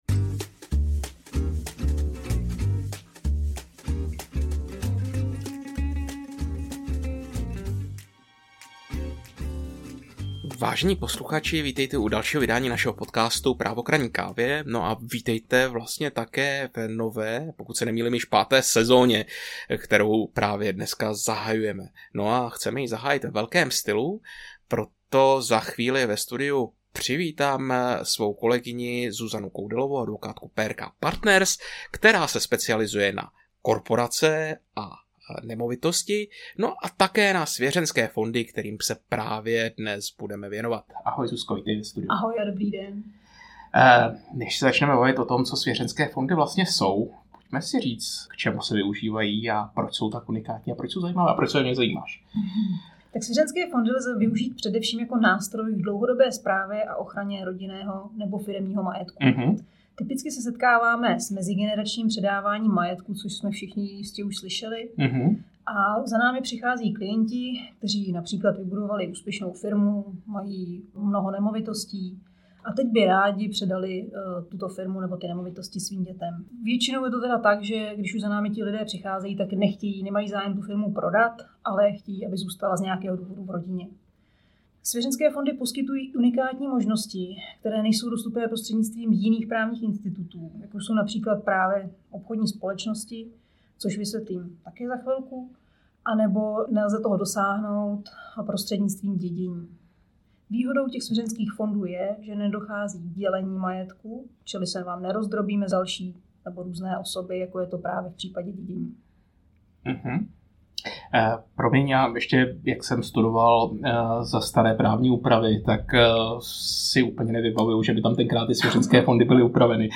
Odpovídají právníci - Právo k Ranní Kávě